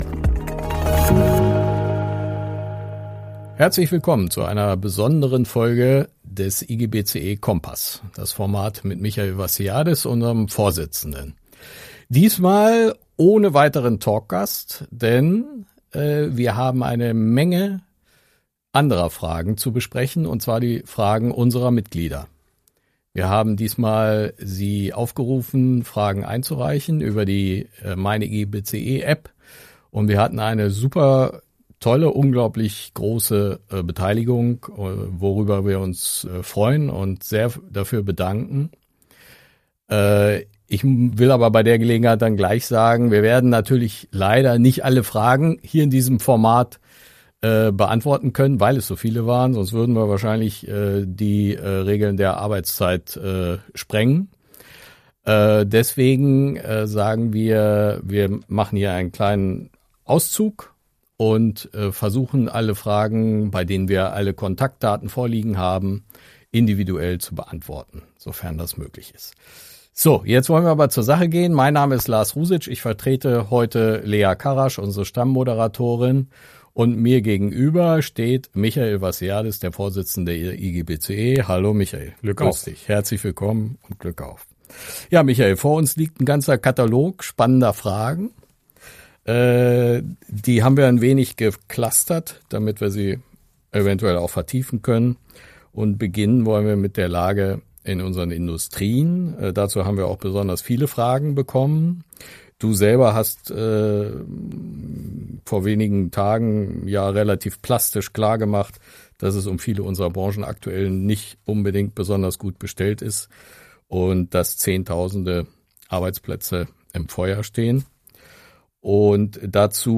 Unsere Mitglieder haben unter großer Beteiligung Fragen an Michael Vassiliadis eingereicht. Im Kompass-Talk beantwortet der IGBCE-Vorsitzende eine breite Auswahl, die wir thematisch gebündelt haben.